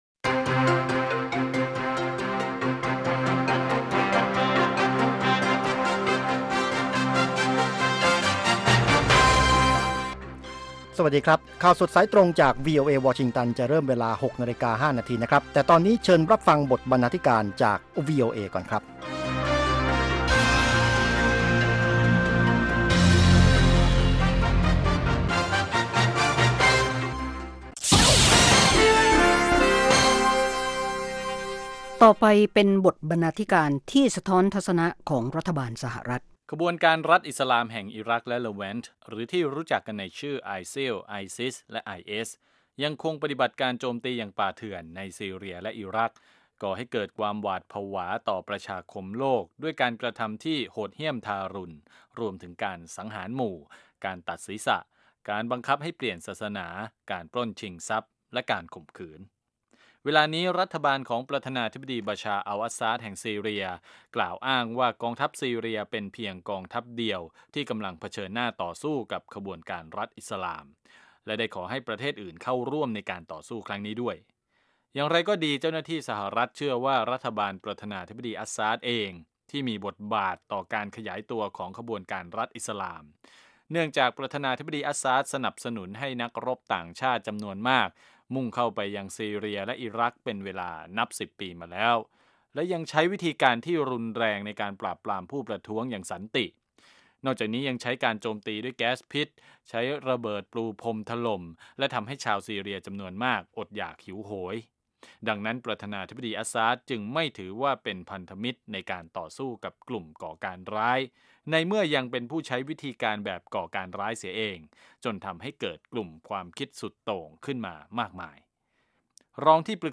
ข่าวสดสายตรงจากวีโอเอ ภาคภาษาไทย 6:00 – 6:30 น.